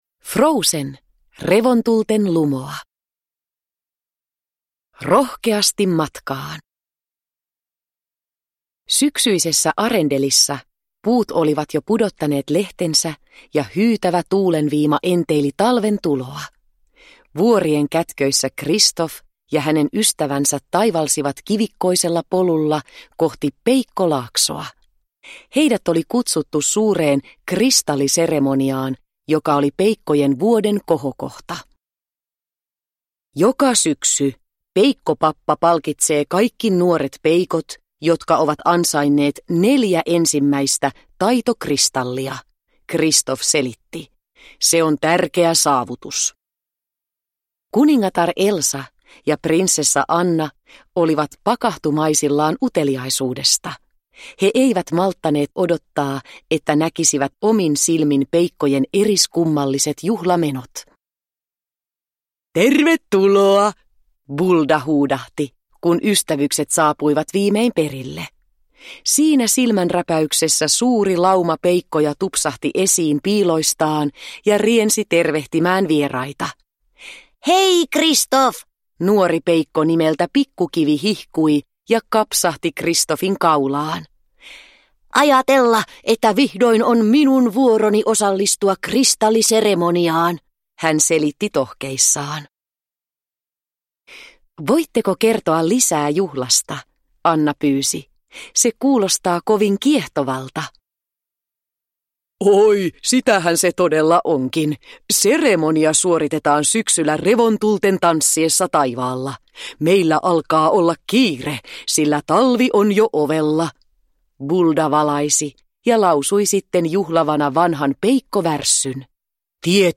Revontulten lumoa – Ljudbok – Laddas ner